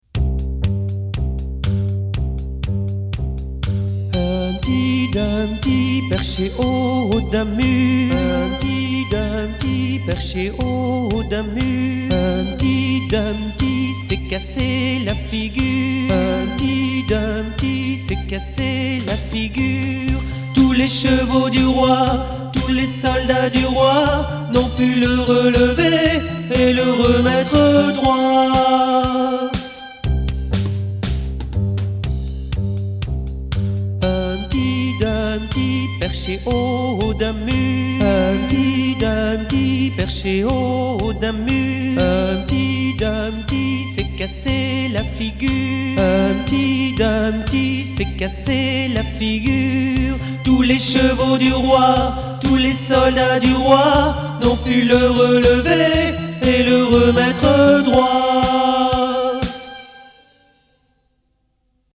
Comptine de ma mère l’oie « Empty Dumpty »